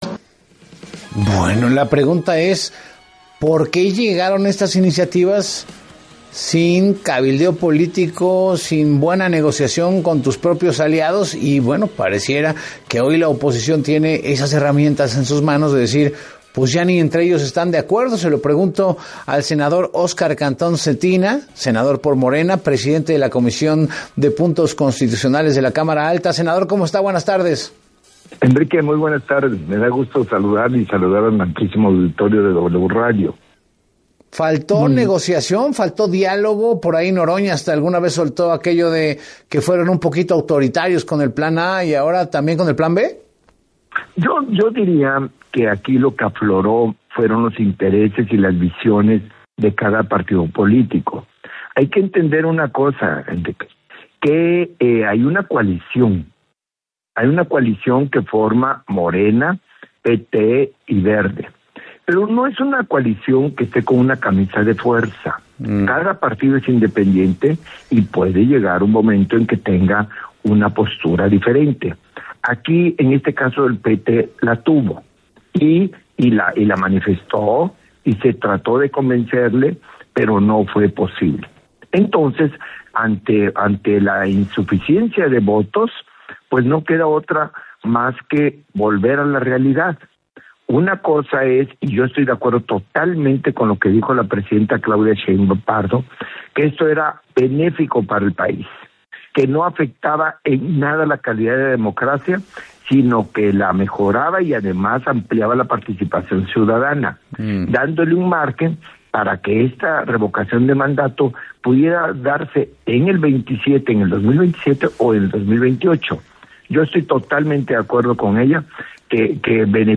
En medio de la discusión por la reforma electoral, el senador Óscar Cantón Zetina reconoció que la independencia del PT y el PVEM obligó a Morena a replantear la iniciativa